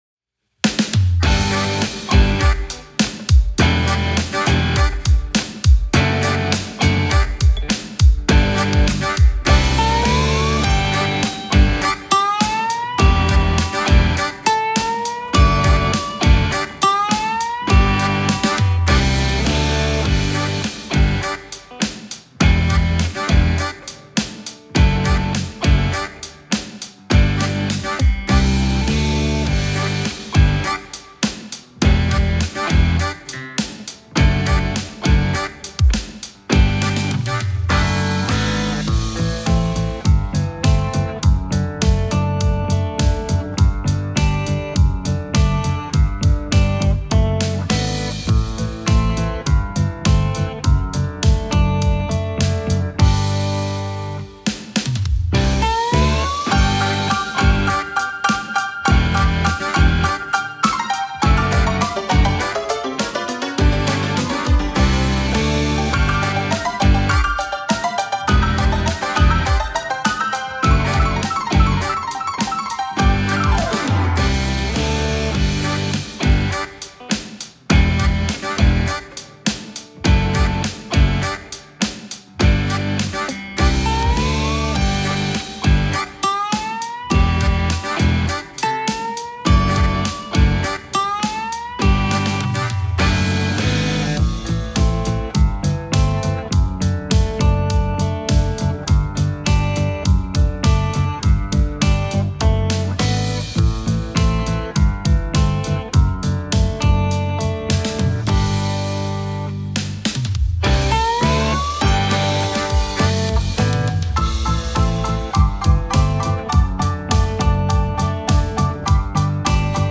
Klamotten-Lied-Playback.mp3